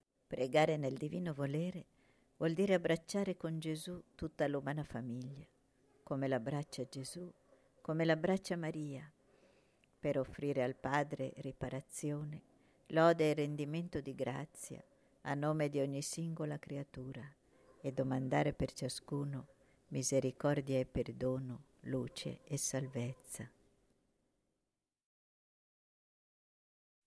nr.6 La luce del Paradiso canto mp3 –